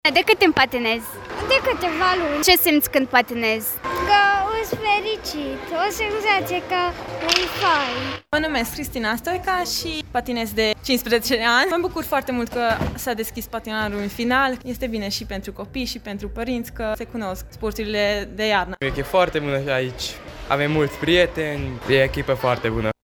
Ieri după-amiază a avut loc deschiderea oficială a noului sezon la patinoarul acoperit ”Mureș” din spatele Complexului de Agrement ”Weekend”.
Într-o arenă mare și răcoroasă, atmosfera a fost caldă și primitoare, iar participanții, indiferent de vârstă, au așteptat nerăbdători să se bucure de deschiderea noului sezon și să înceapă antrenamentele. Zeci de copii însoțiți de părinți și-au manifestat dorința să urce pe gheață, alături de antrenorii lor: